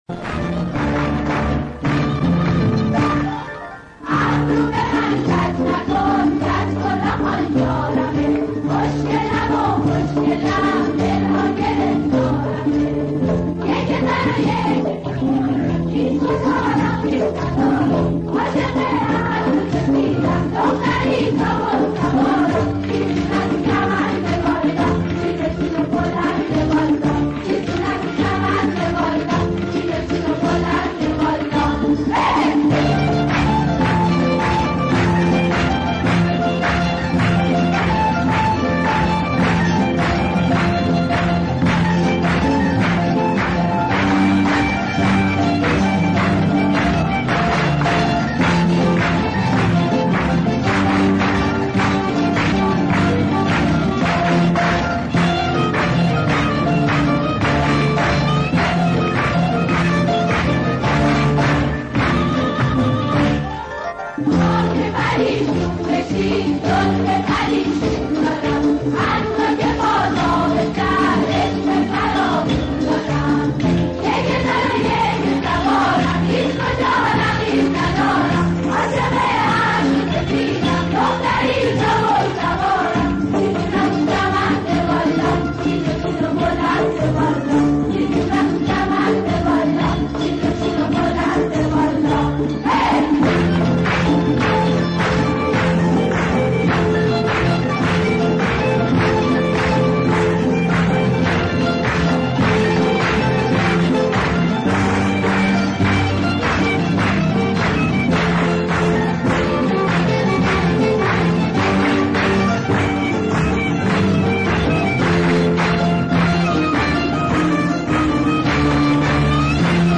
ابرو ب من کج نکن..گروه کر